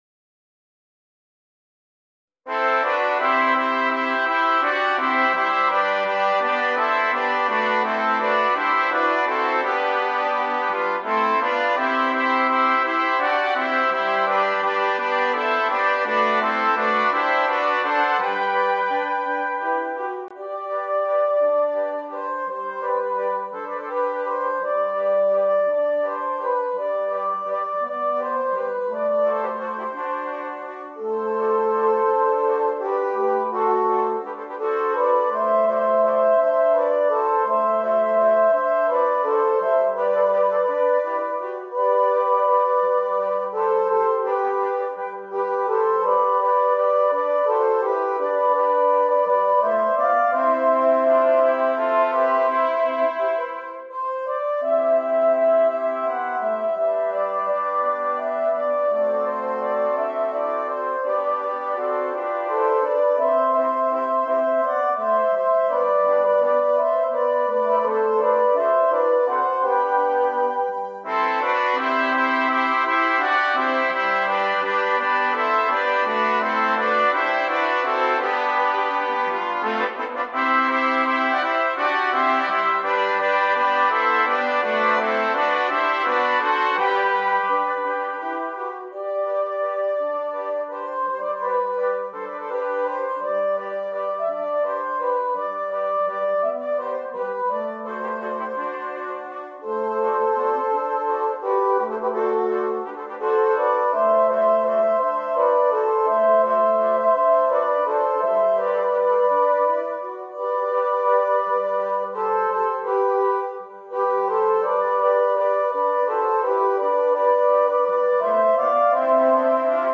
7 Trumpets
Traditional